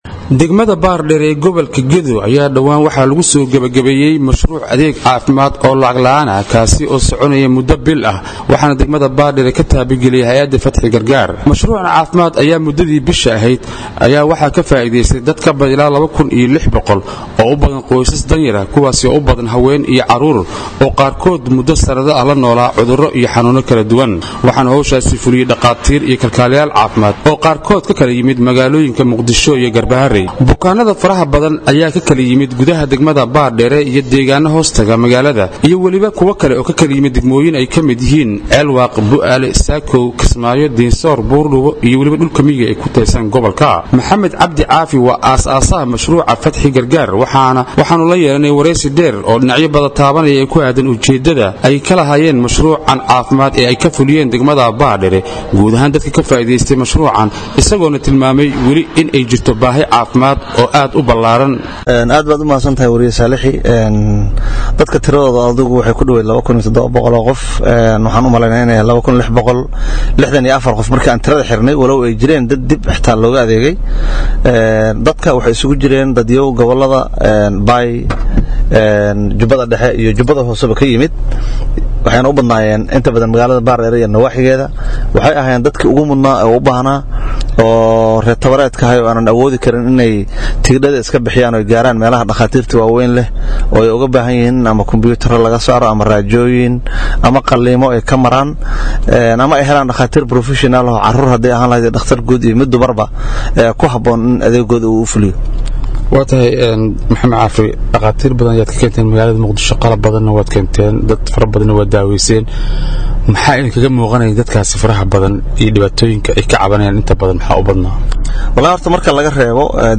Wareysi>Dad ka badan ilaa 2600 oo ruux ayaa Adeegyo Caafimaad oo Bilaash ah loogu sameeyey Bishii hore Baardheere.
WARBIXIN-FATXI-GARGAAR-.mp3